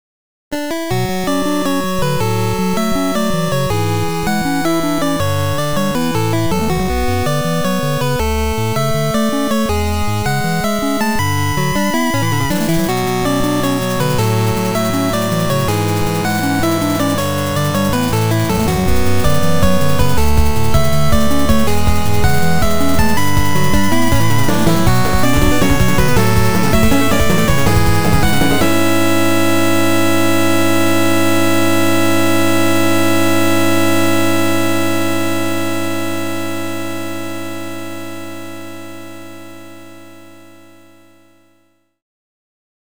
ファミコン音源アレンジ
このページの楽曲は全て、フリーのFC音源サウンドドライバppmckを使って制作しています。